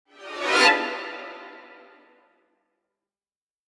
Scary String Tension Sound Button - Free Download & Play
Sound Effects Soundboard263 views